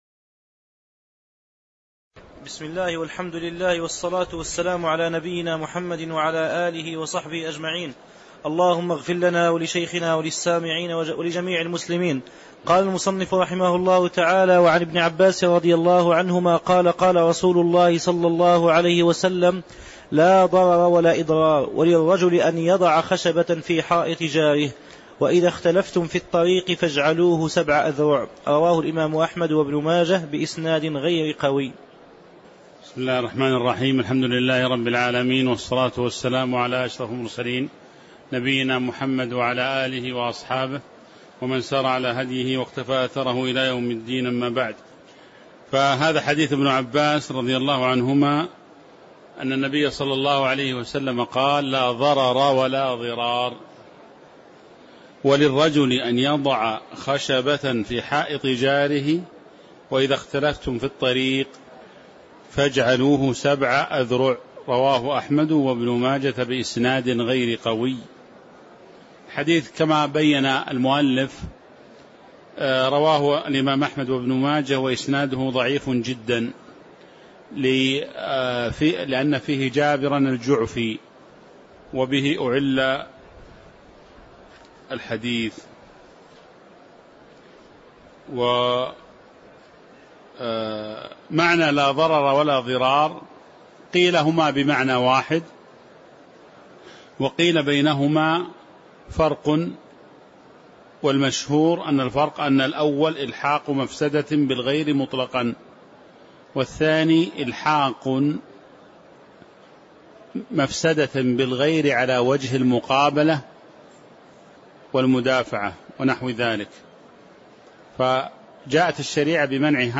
تاريخ النشر ١٦ شوال ١٤٤٦ هـ المكان: المسجد النبوي الشيخ